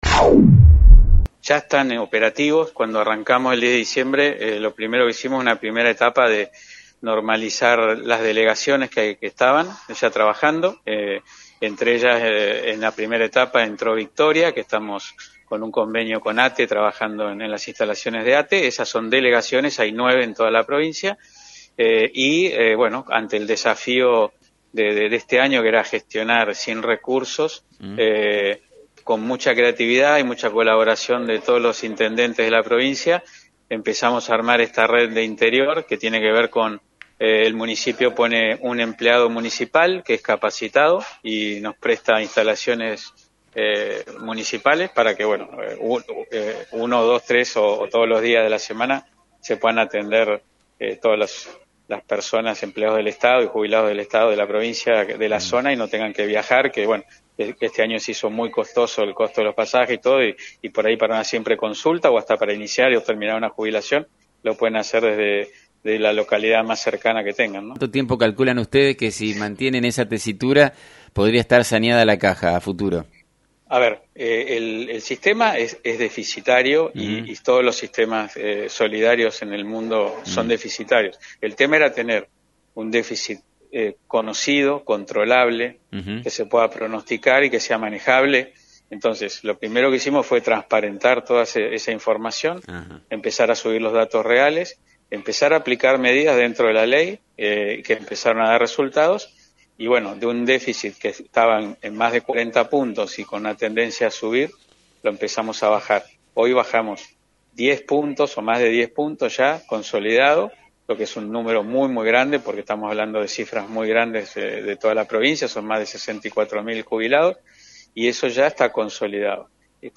“Necesitabamos gestionar con creatividad para esta red interior, donde los municipios ponen un empleado municipal, que nosotros capacitados, y nos prestan intalaciones para que se pueda atender a los empleados y jubilados de la provincia”, explicó Gastón Bagnat, en diálogo con FM 90.3, quien reconoció los costos que tienen para cualquier persona trasladarse a la capital de la provincia, por trámites que en su gran mayoría podrán ahora realizarse en su propia ciudad.